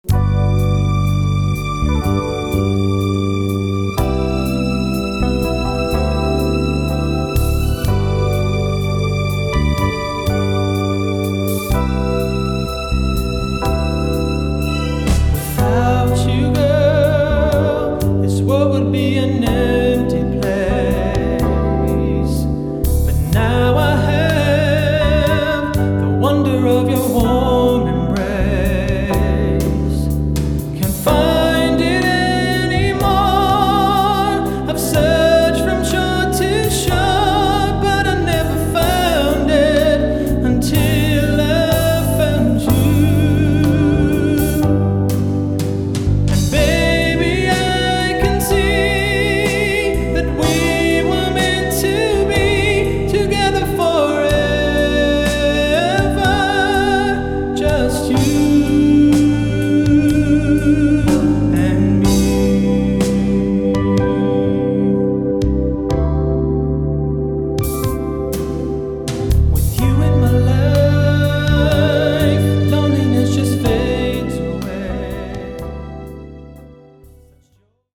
tenor voice